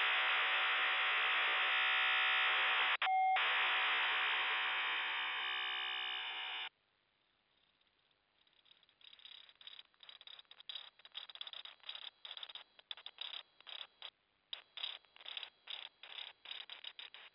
Начало » Записи » Радиоcигналы на опознание и анализ
КВ-сигнал с полосой 50 кГц